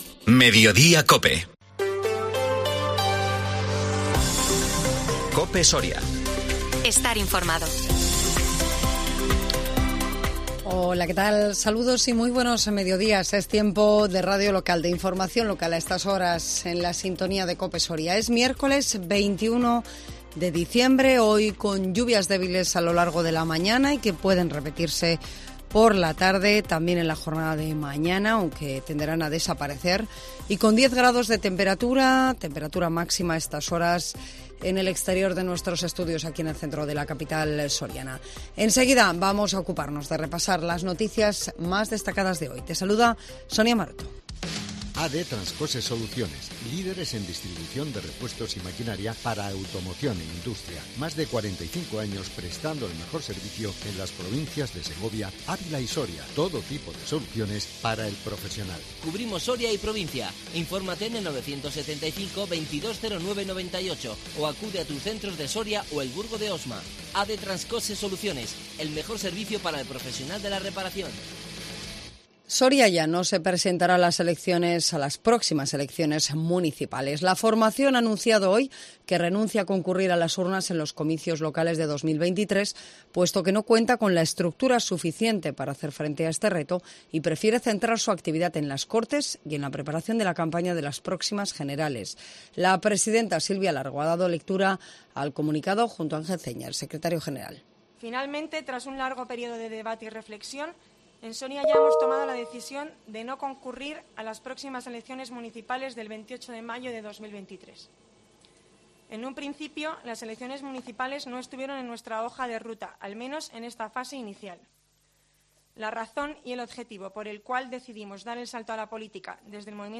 INFORMATIVO MEDIODÍA COPE SORIA 21 DICIEMBRE 2022